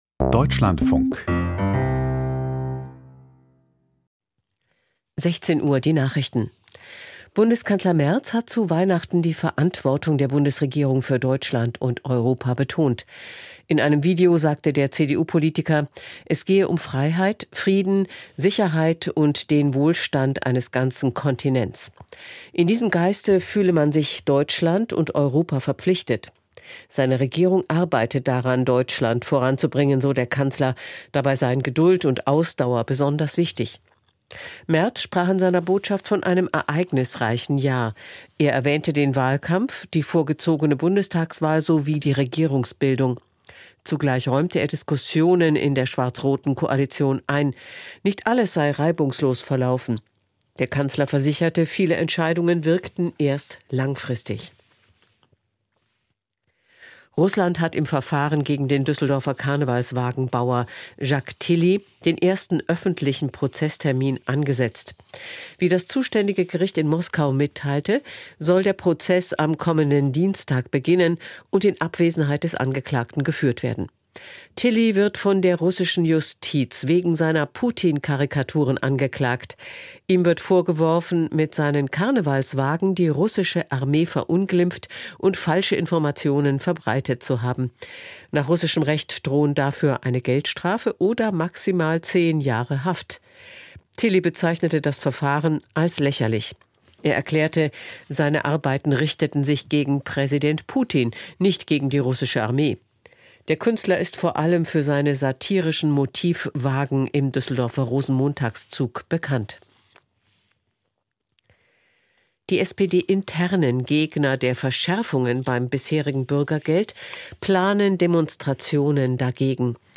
Die Nachrichten vom 24.12.2025, 16:00 Uhr